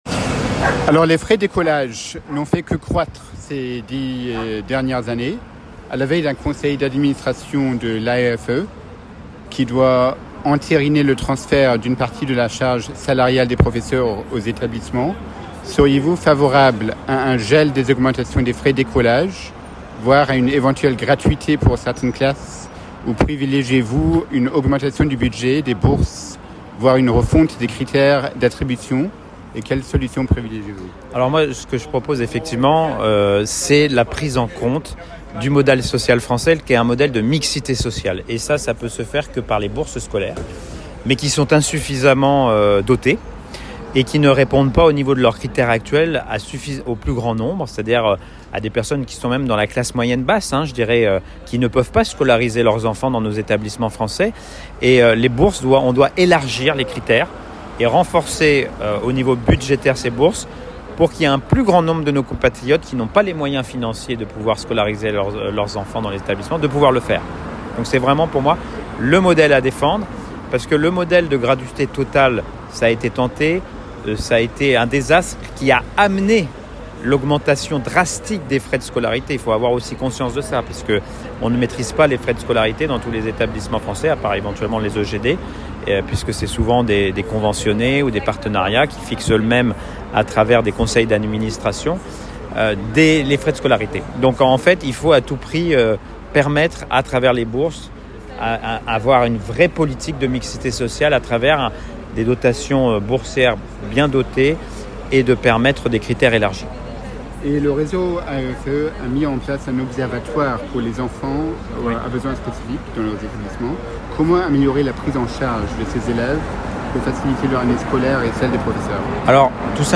Yan Chantrel, sénateur des Français établis hors de France